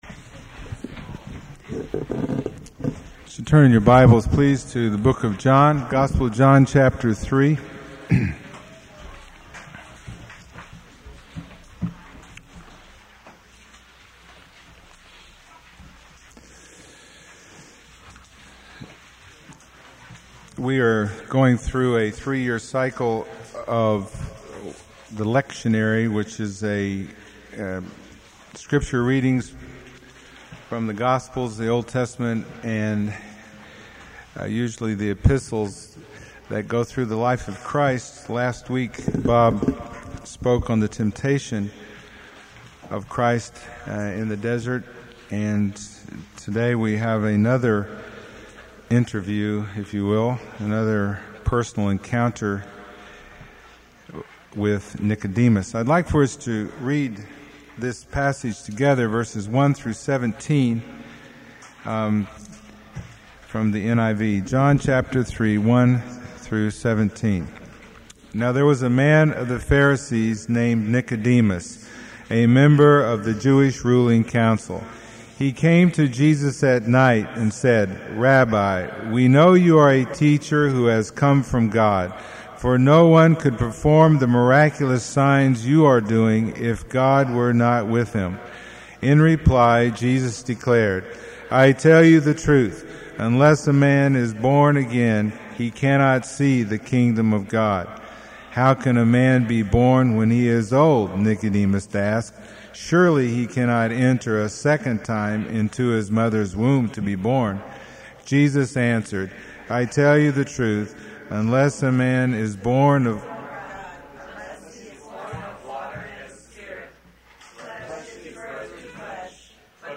No Series Passage: John 3:1-17 Service Type: Sunday Morning %todo_render% « Command To Be Holy